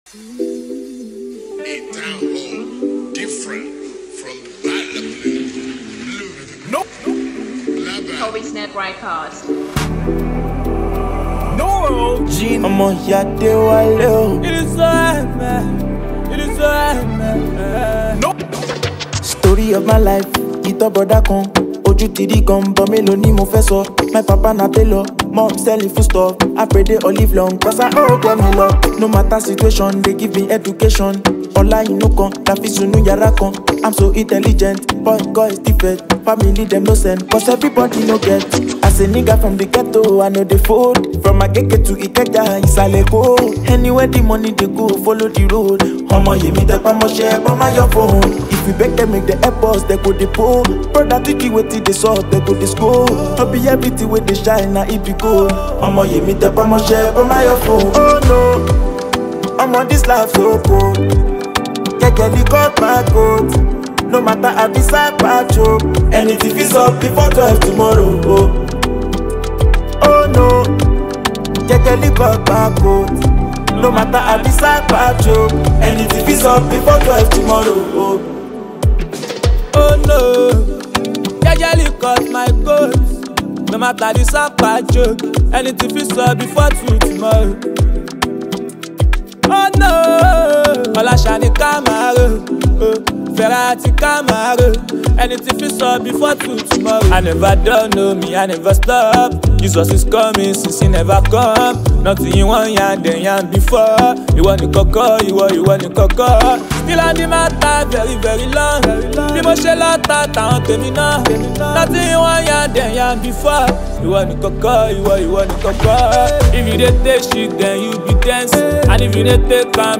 soul-stirring Afrobeat anthem